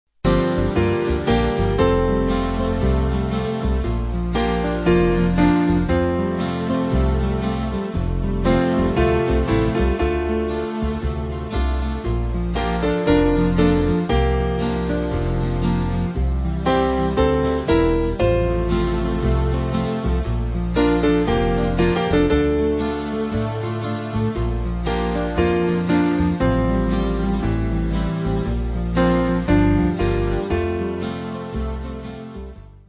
Small Band
Slight lilt